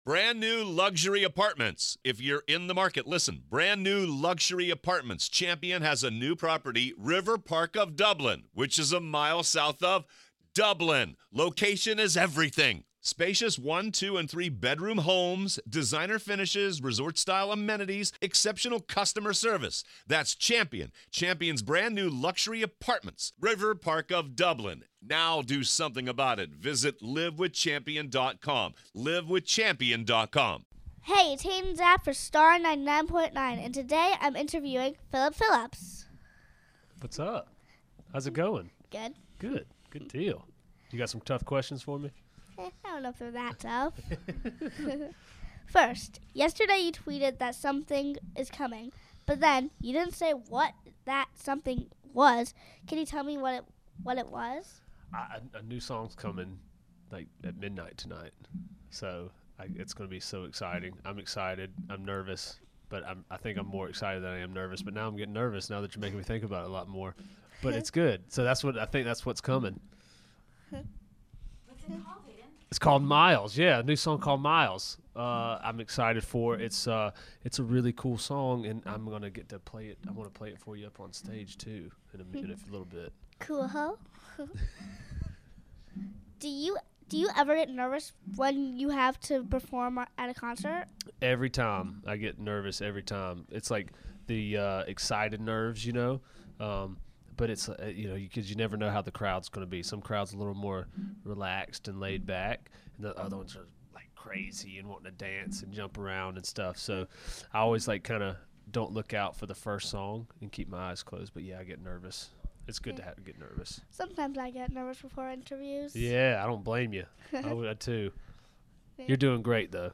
The full, un cut version of the interview